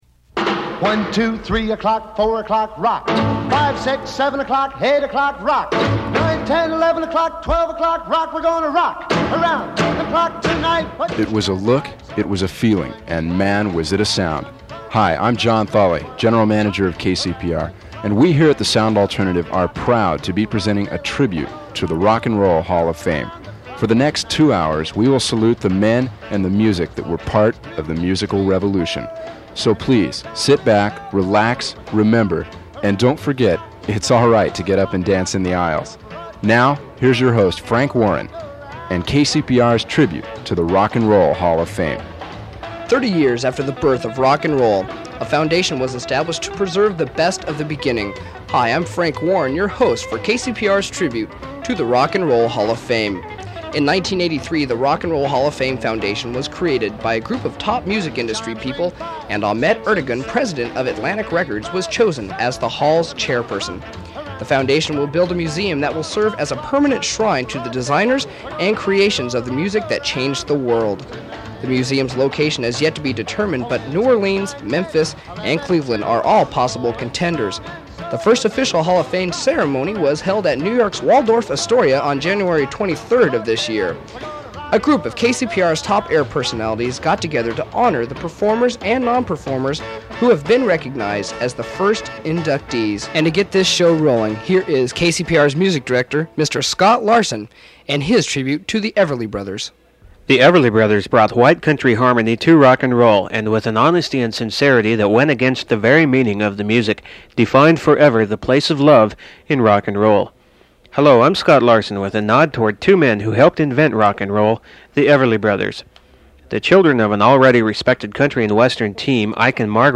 Form of original Open reel audiotape